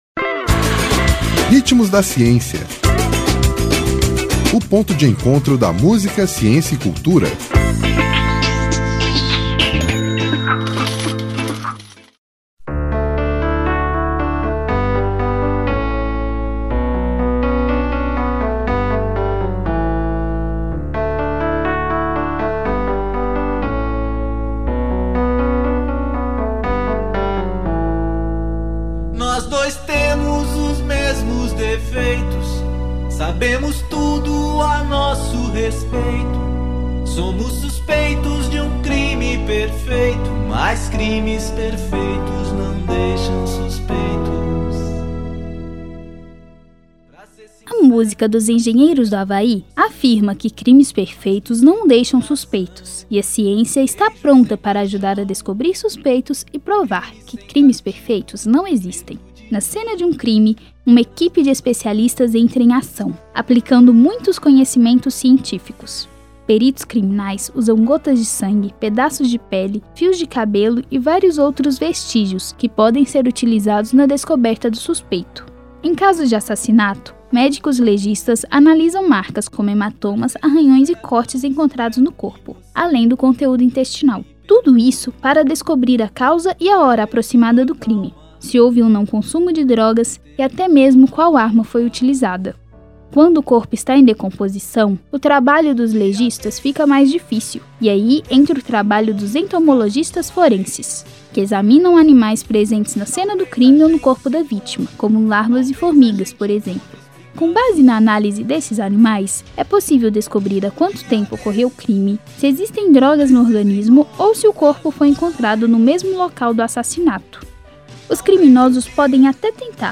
Nome da música: Pra Ser Sincero
Intérprete: Engenheiros do Hawaii